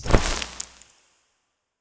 Inventory_Open_01.wav